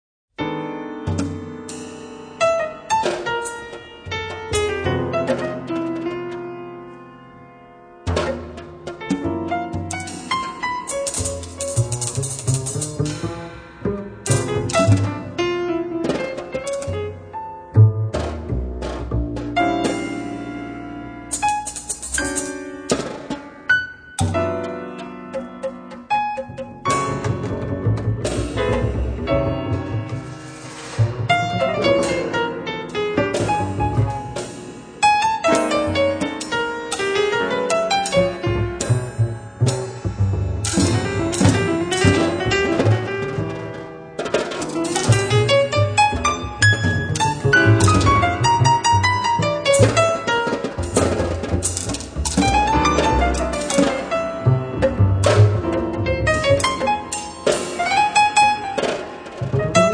pianoforte
contrabbasso
batteria